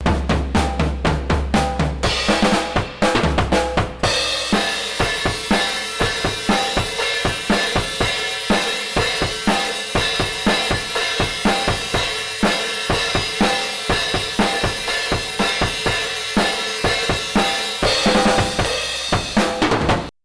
とりあえず去年遊びで録ったドラムで試してみた
主にドラムが
こいつを一番歪まない設定で左右二本に分けて録って、音量もそれなりに揃えてみた
もうちょいゲイン上げたのがこれ
ここまでいくと大分痩せてくるな